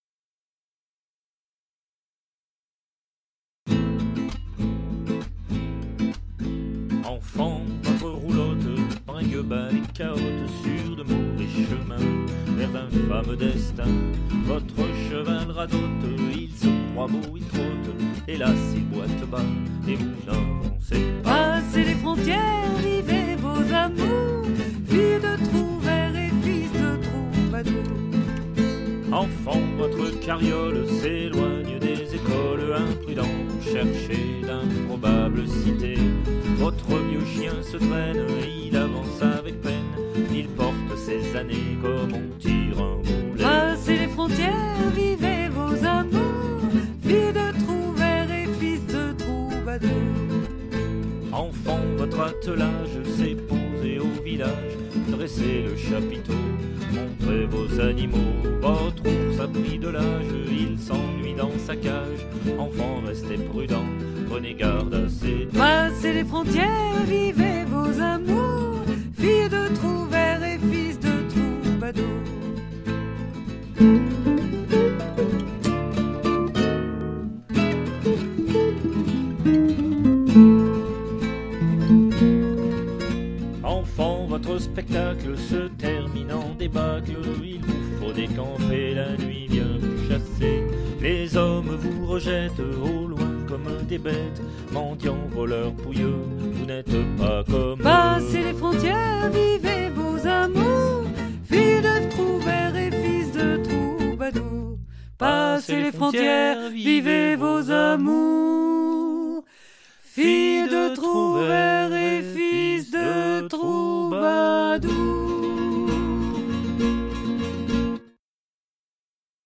ORCHESTRA+VOIX1+VOIX2.mp3